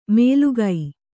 ♪ mēlugai